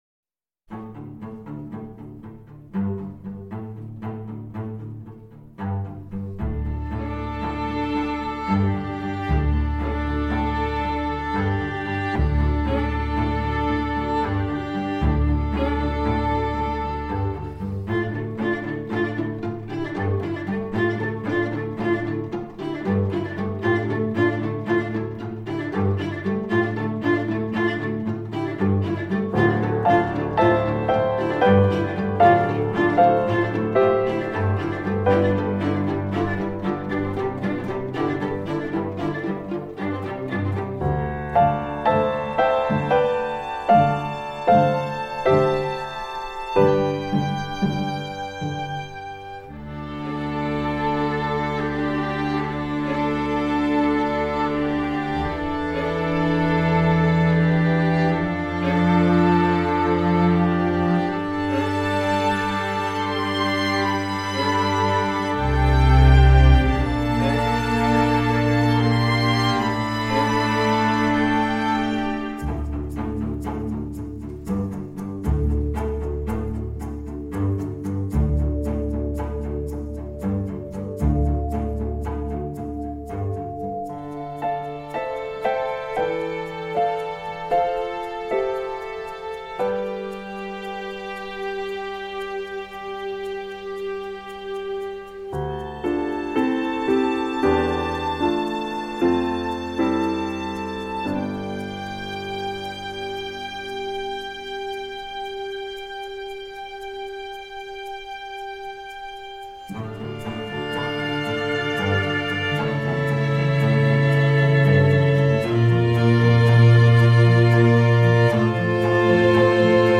surtout avec les cordes et quelques effets synthétiques